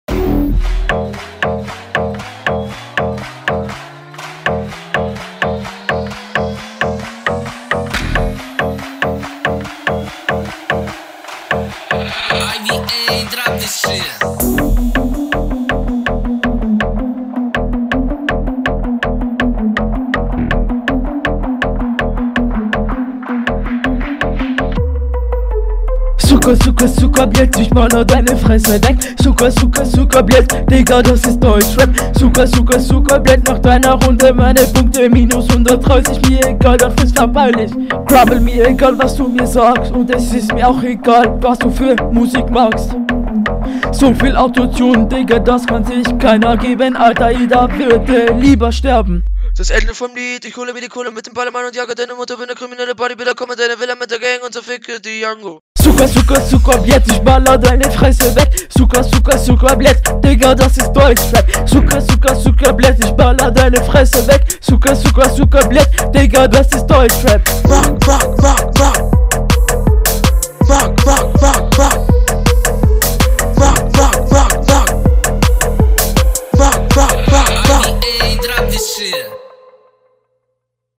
Flow: ist nicht so gut wie bei'm gegner und die stimme hat auch nicht so …